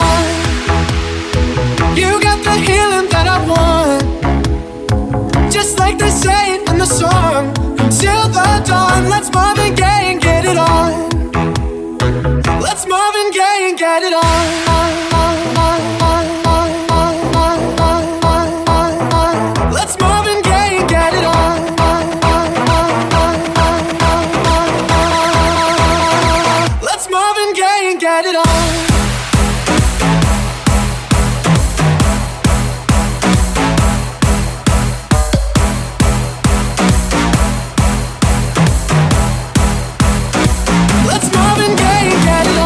Genere: deep house, tropical, house, club, edm, remix